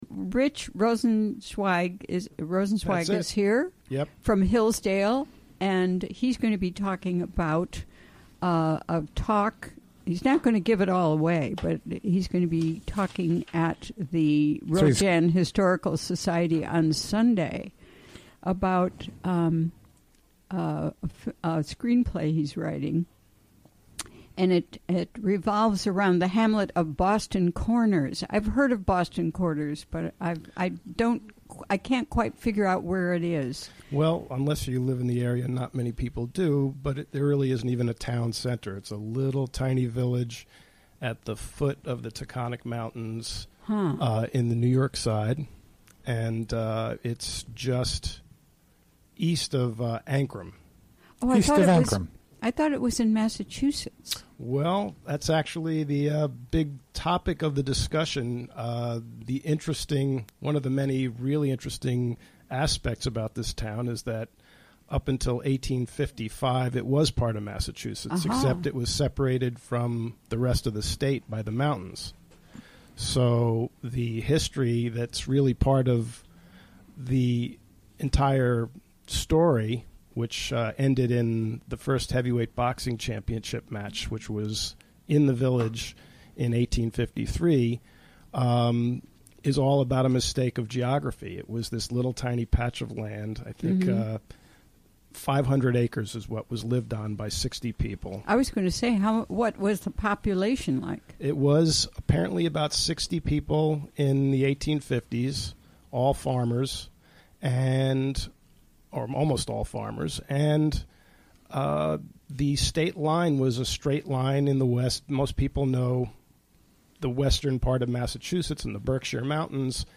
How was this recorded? Recorded during the WGXC Afternoon Show Thursday, March 9, 2017.